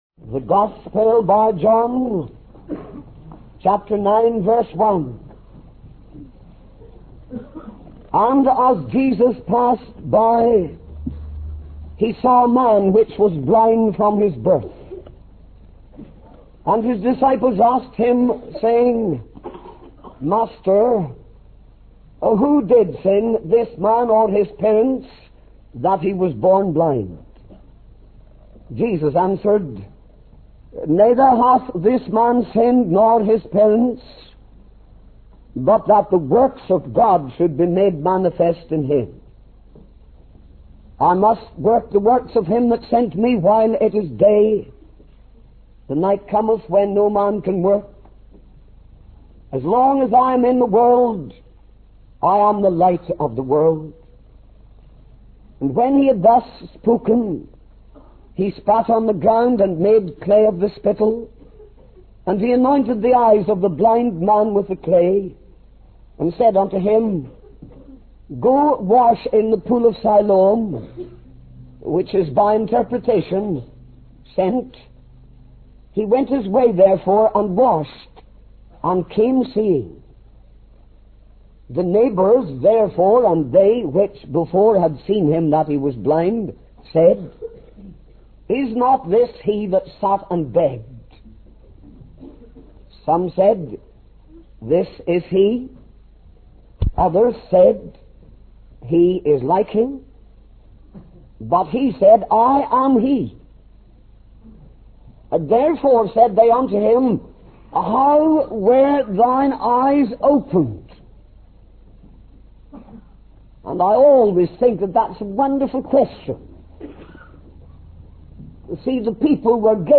In this sermon, the speaker shares his personal journey of coming to know the Savior. He emphasizes that there are many different paths that lead to Christ and encourages young believers not to be discouraged if their own experiences differ from his.